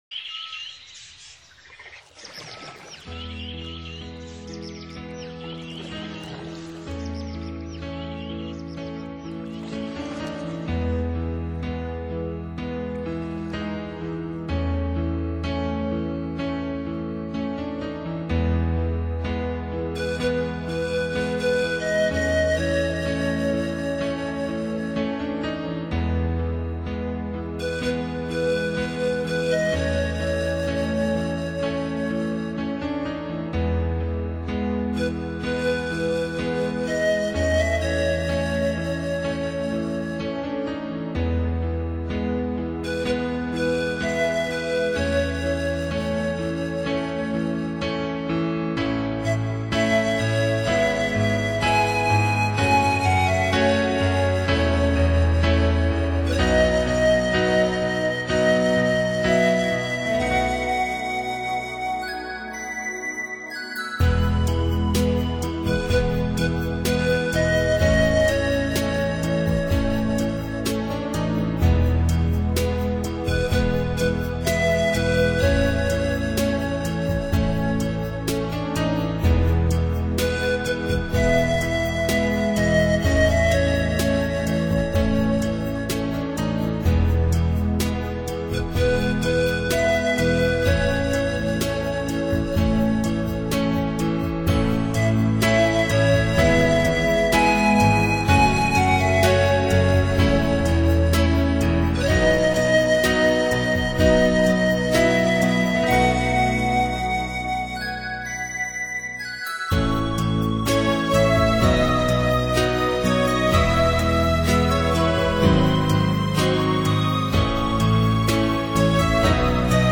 专辑语言：纯音乐
淡淡的音乐，自然的氛围